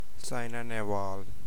Saina_Nehwal.ogg.mp3